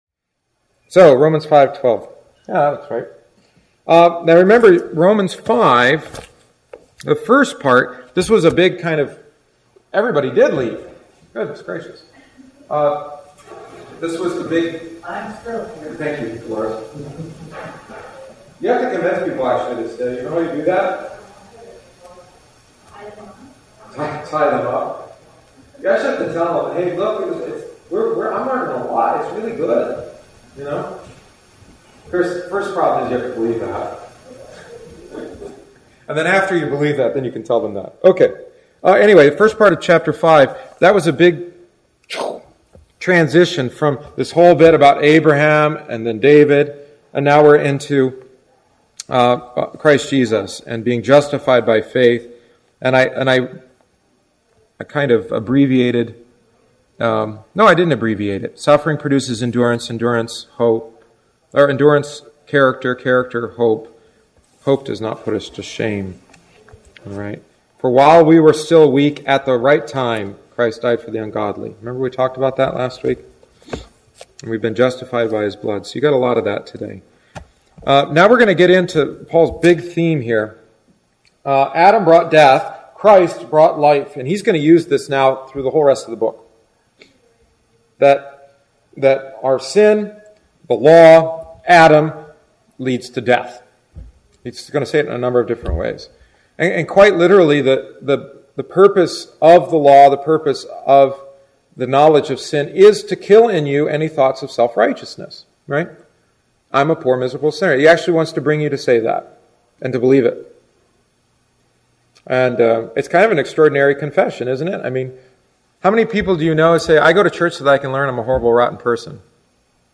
The following is the thirteenth week’s lesson.